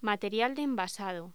Locución: Material de envasado
voz